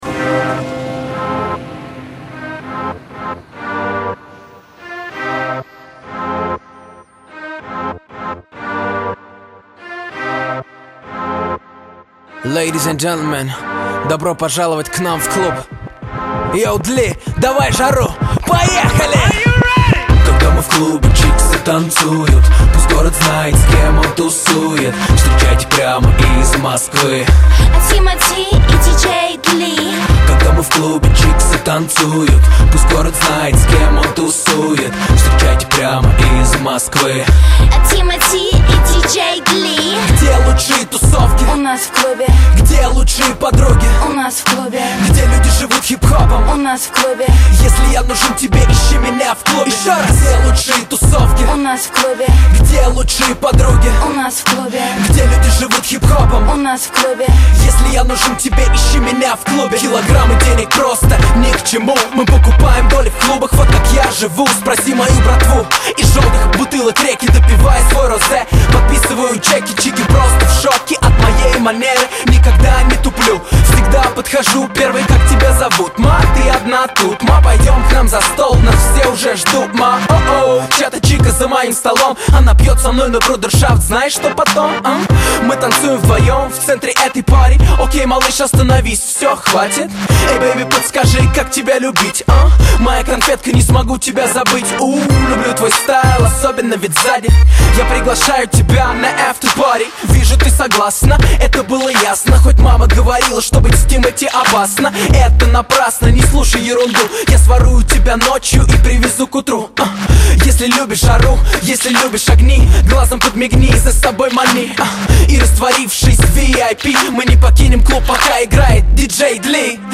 Categoria: Rap